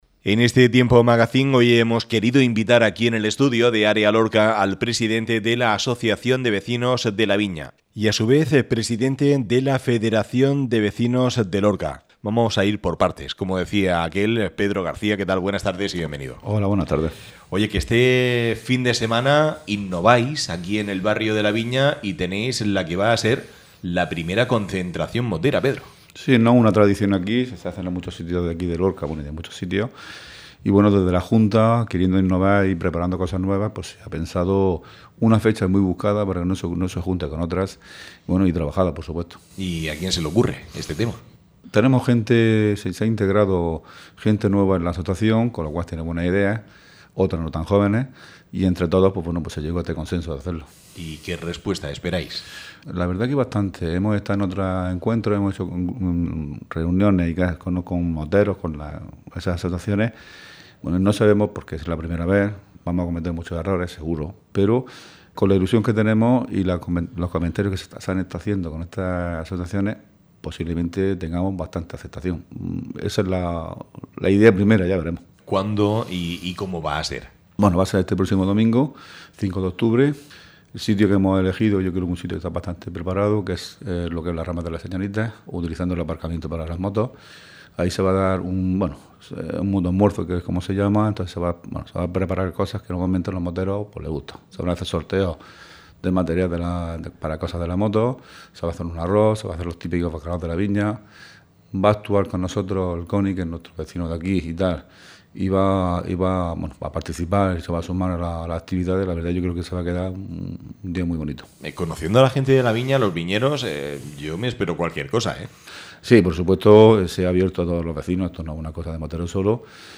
en el magazine de Área Lorca Radio